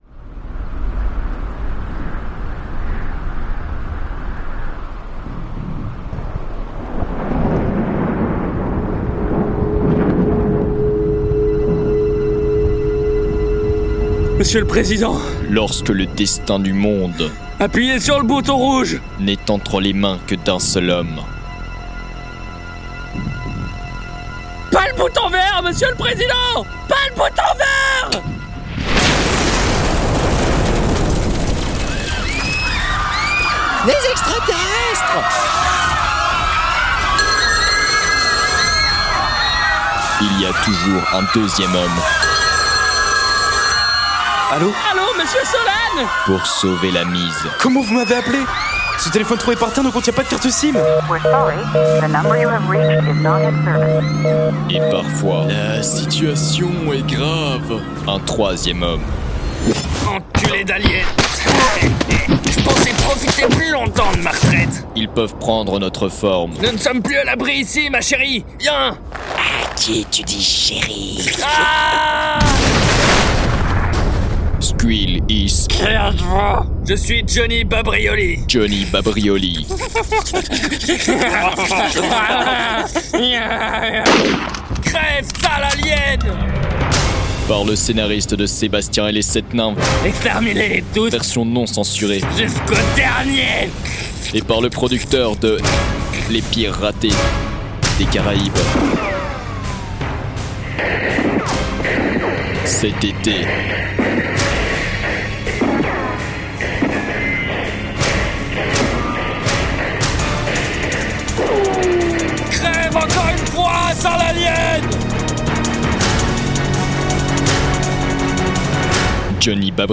Jeu de mixage - Bandes-annonces
• Utiliser un Deesser
• Utiliser une reverb